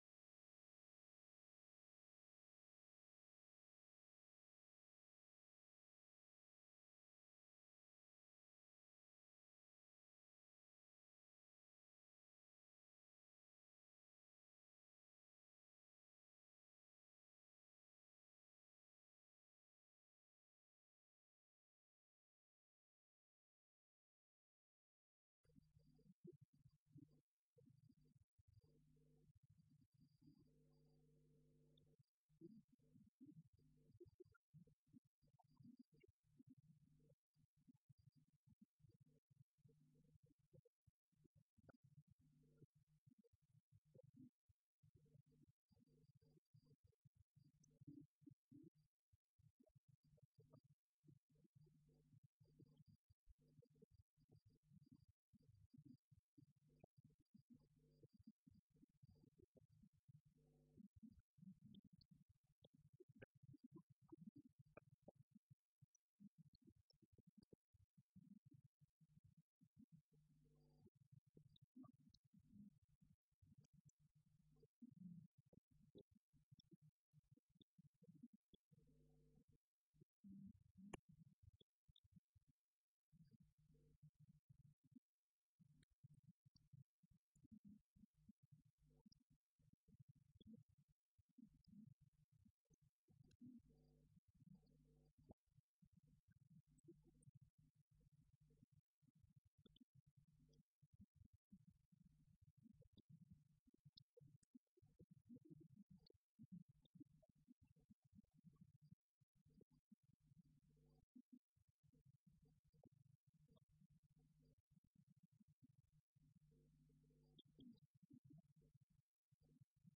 05 Débat La philosophie dans le monde arabo-musulman au Moyen Âge | Canal U